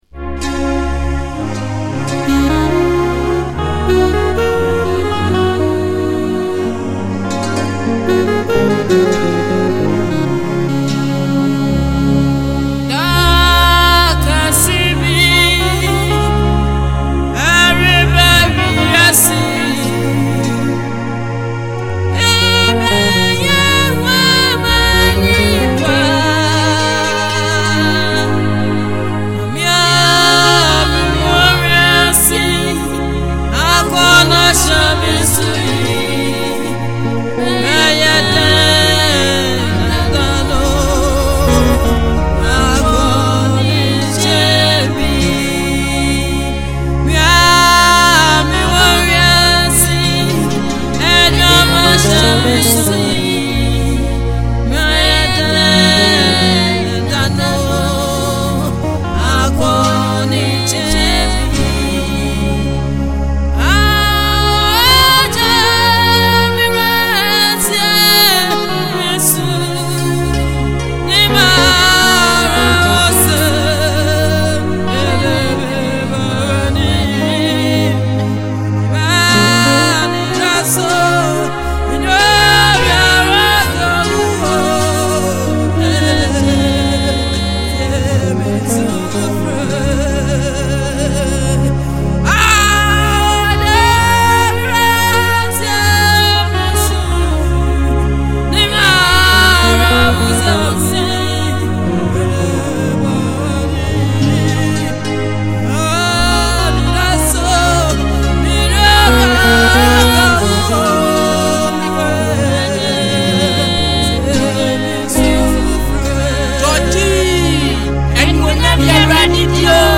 Ghanaian Gospel
This soulful track
powerful vocals and emotional delivery
With its catchy melody and heartfelt lyrics